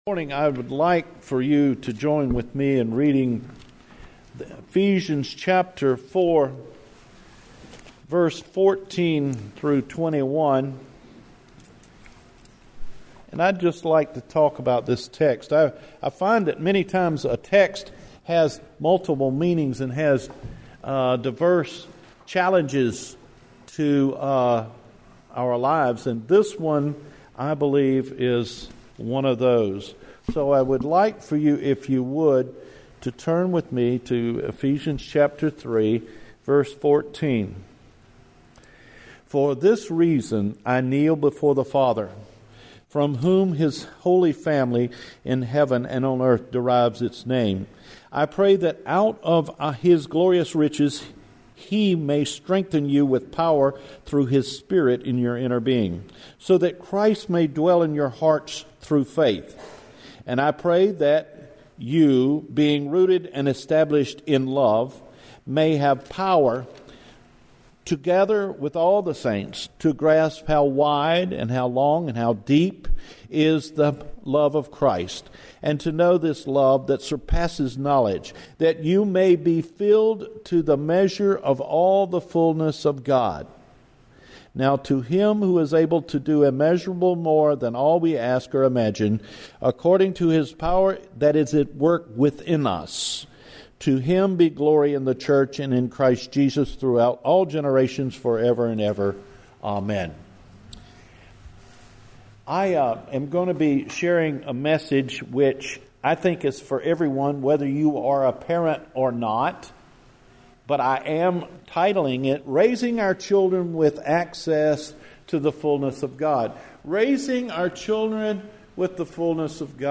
Raising Our Children With the Fullness of God – August 19 Sermon